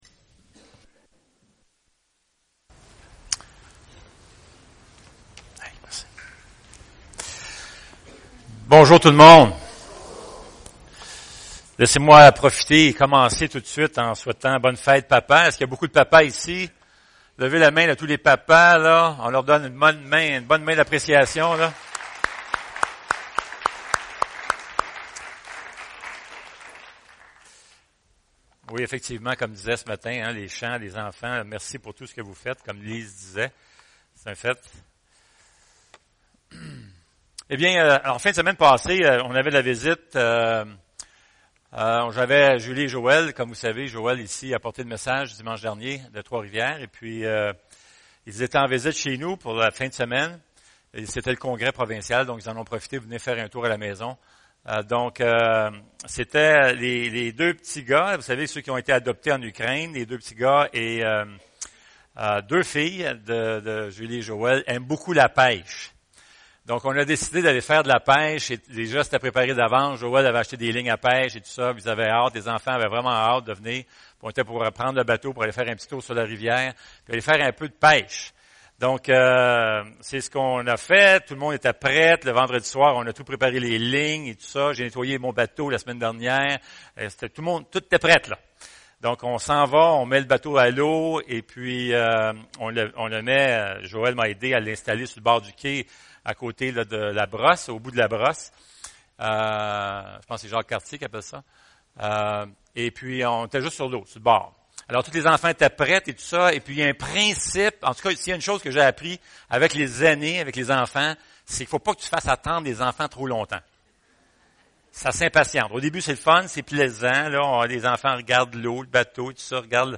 Bible Text: Psaume 78 | Enseignant